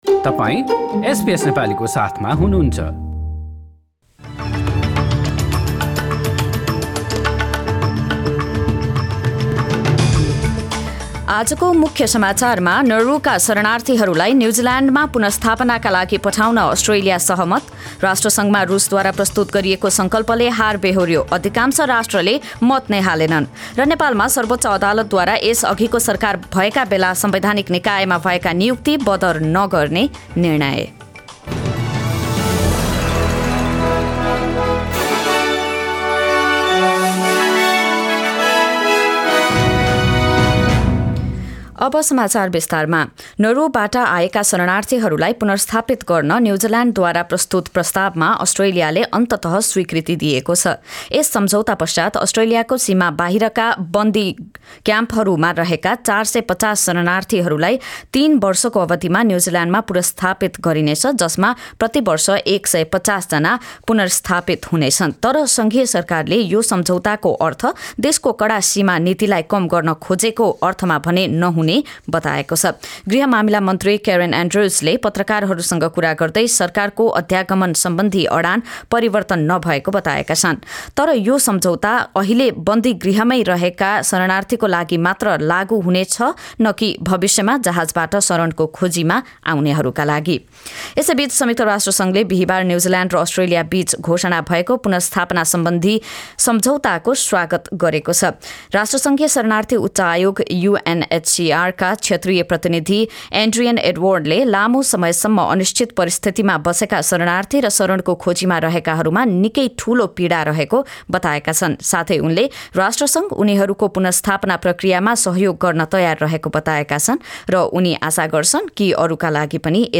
एसबीएस नेपाली अस्ट्रेलिया समाचार: बिहीवार २४ मार्च २०२२